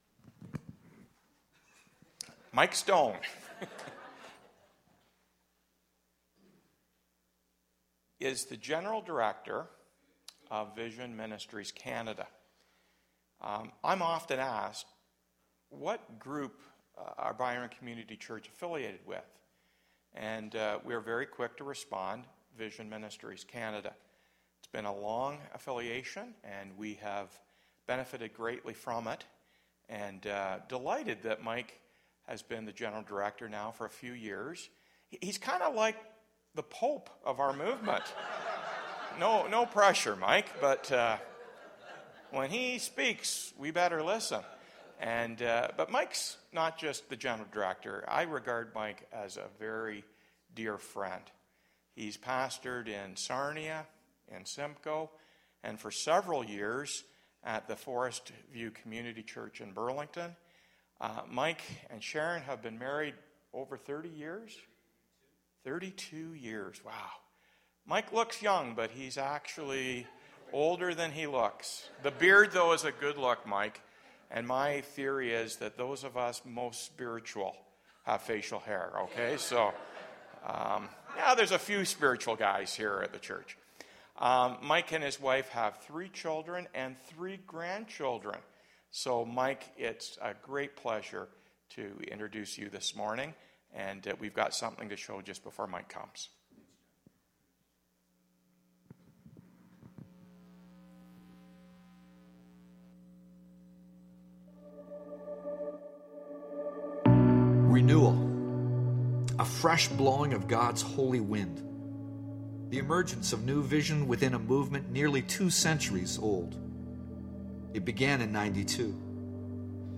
Byron Community Church is an Evangelical Chuch located in the south west side of London, Ontario